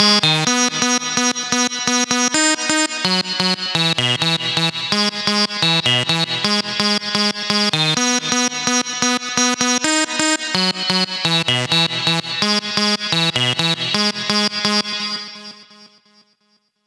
VTDS2 Song Kit 15 Vocoder Mother Earth Sequence.wav